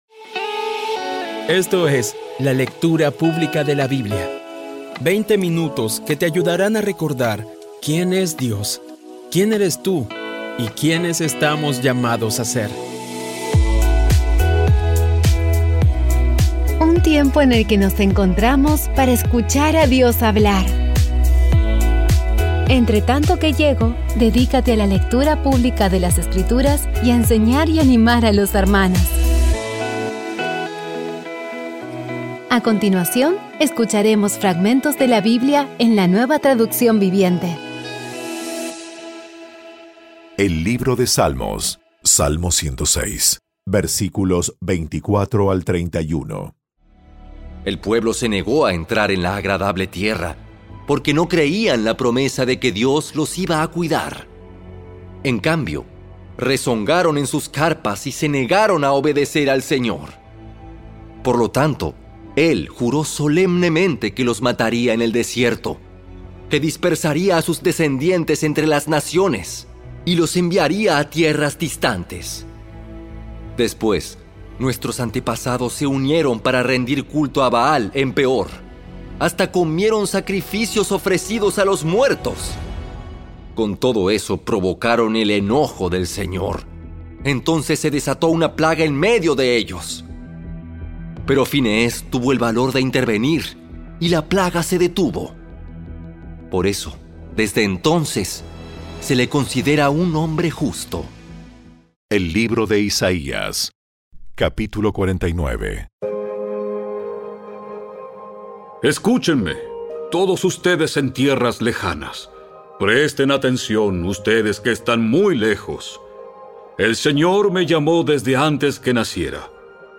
Audio Biblia Dramatizada Episodio 265
Poco a poco y con las maravillosas voces actuadas de los protagonistas vas degustando las palabras de esa guía que Dios nos dio.